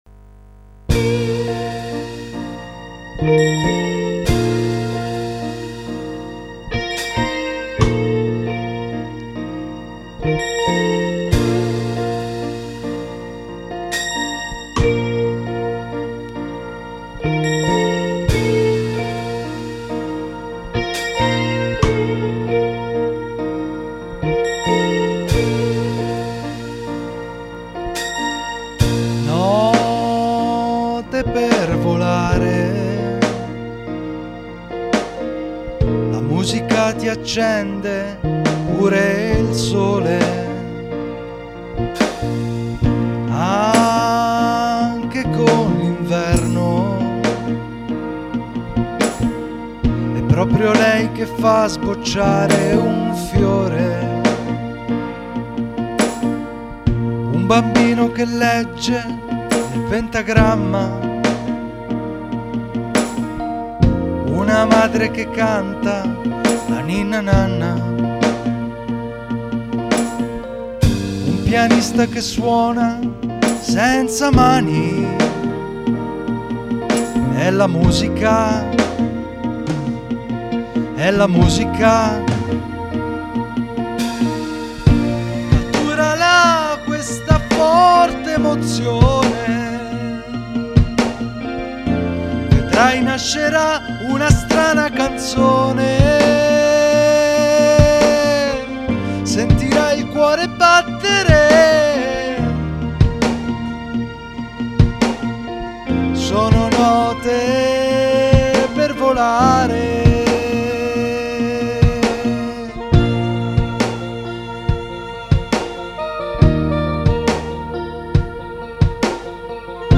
GenerePop / Musica Leggera